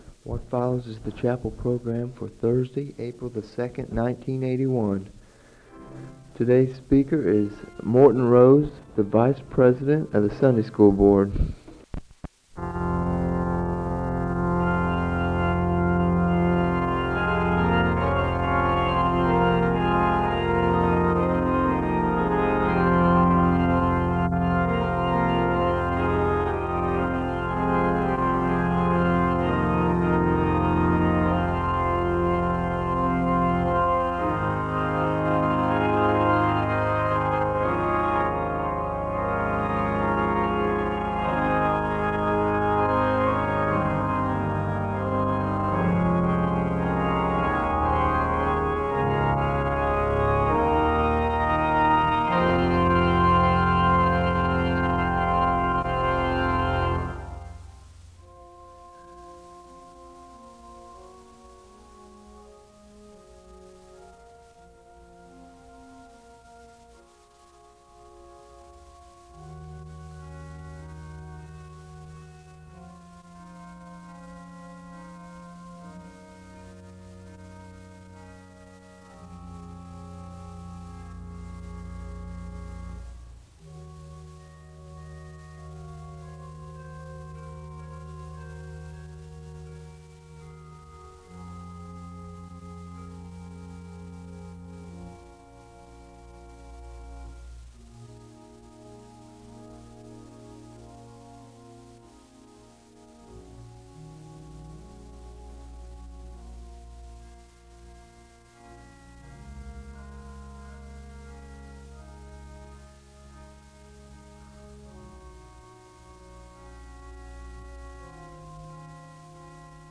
The service begins with organ music (00:00-03:51). The speaker reads from John’s gospel, and he gives a word of prayer (03:52-06:18).
The choir sings a song of worship (07:30-10:32).